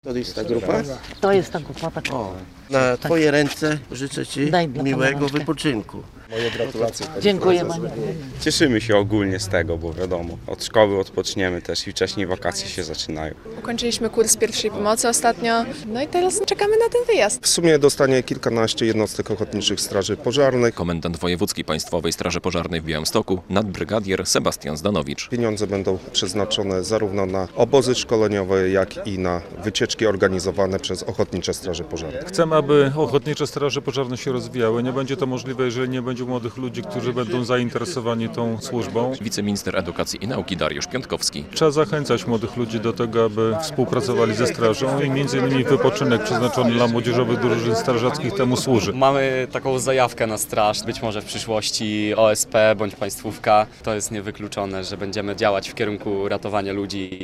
Dofinansowanie dla młodych strażaków - relacja